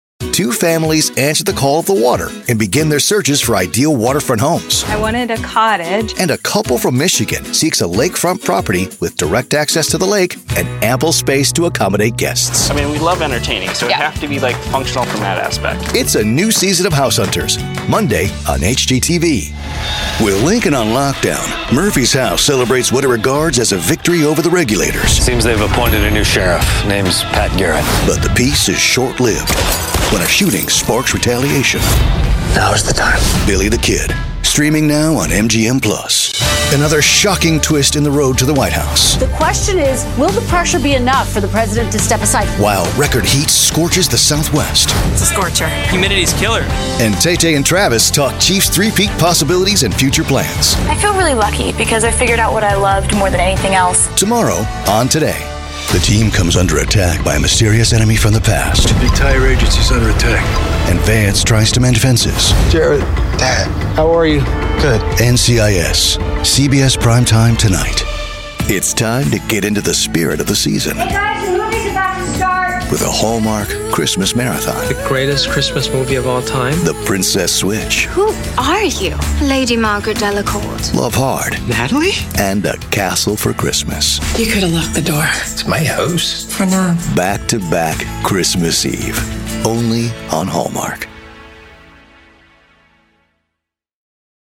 Easy-going, Guy-Next-Door, Conversational.
Promo